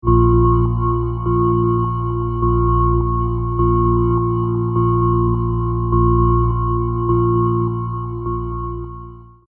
Audio therapy (Flizoar expanse)